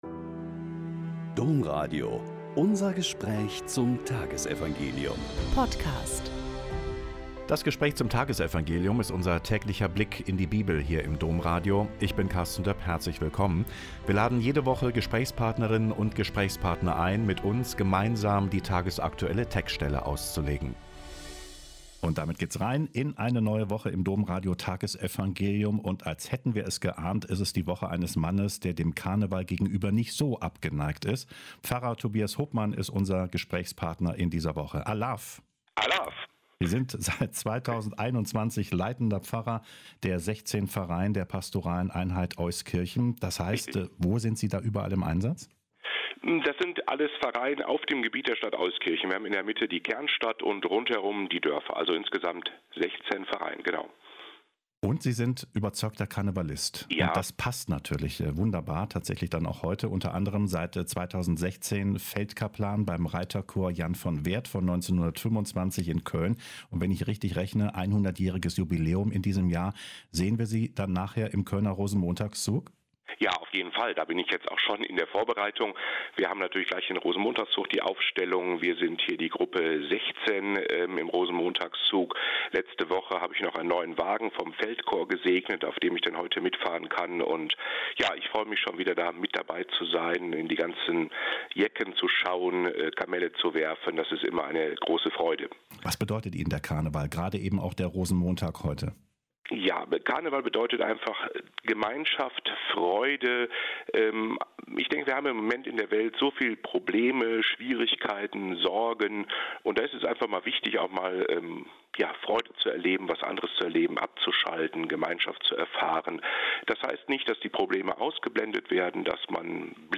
Mk 10,17-27 - Gespräch